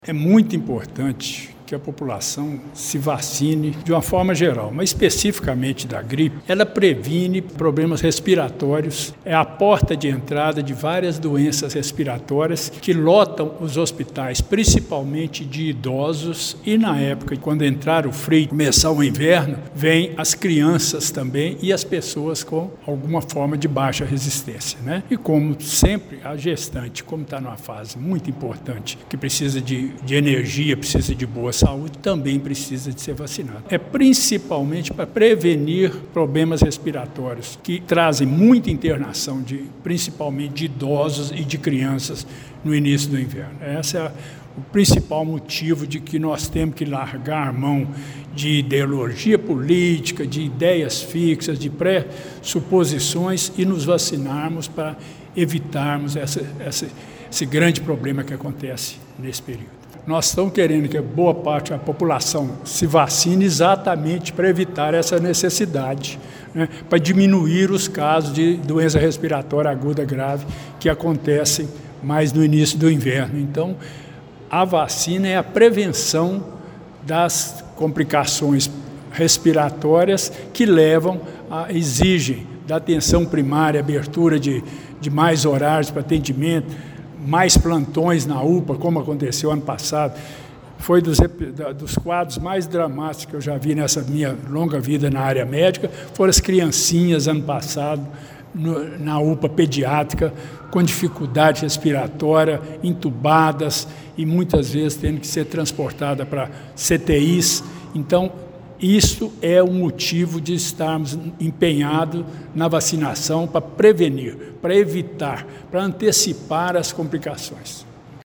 O cenário do ano passado, marcado por unidades sentinelas e alta ocupação na rede de saúde devido à Síndrome Respiratória Aguda Grave (SRAG), serve de alerta para a atual mobilização. O secretário municipal de Saúde, Gilberto Denoziro Valadares da Silva, destacou que a vacina é a ferramenta fundamental para evitar o colapso do sistema e o sofrimento das famílias.